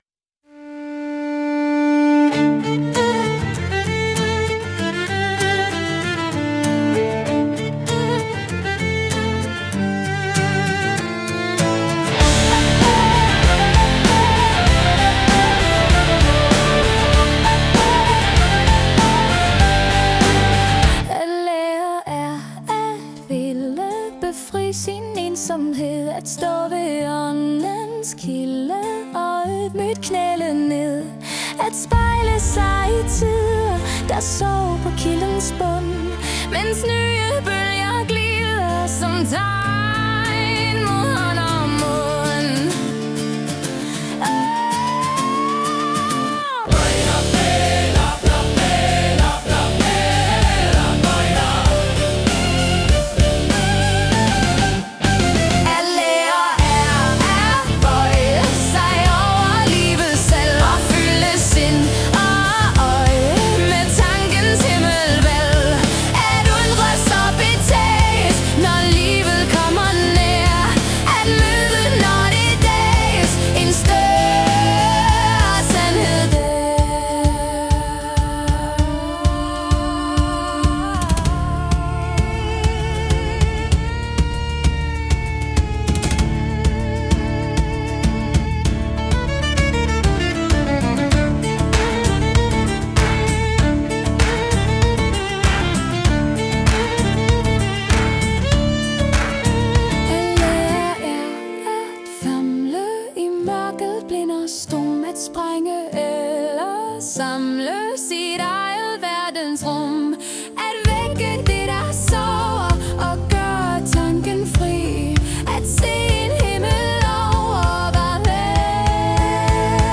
Metal symphoni